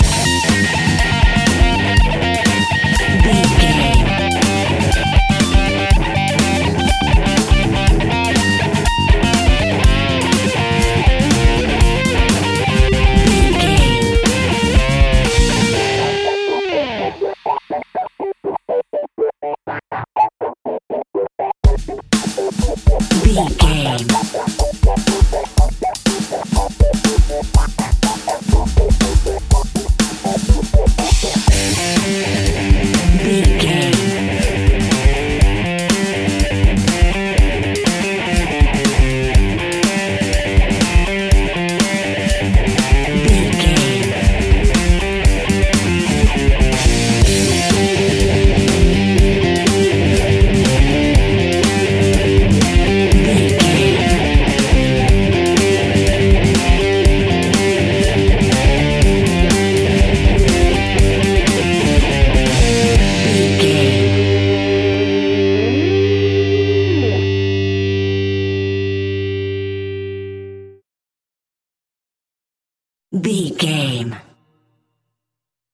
Aeolian/Minor
hard rock
blues rock
Rock Bass
heavy drums
distorted guitars
hammond organ